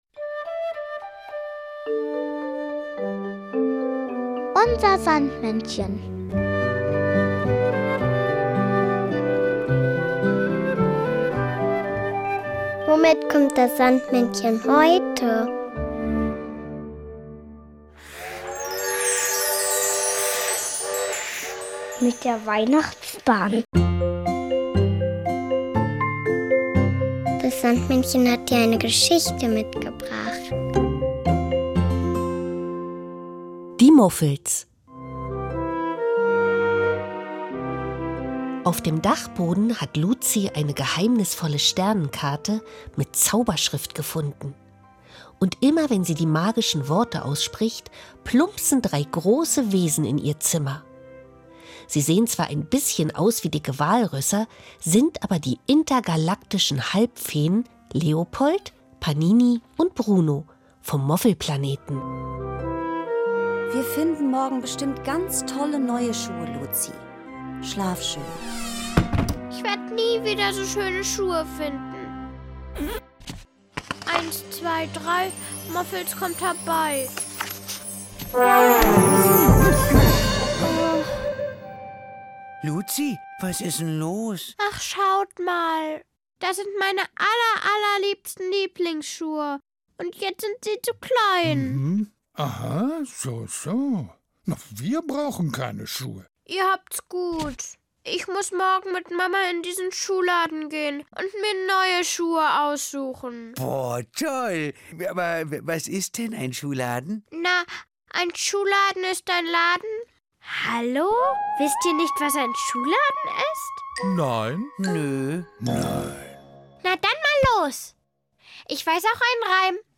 mitgebracht, sondern auch noch das Weihnachtslied "Morgen kommt der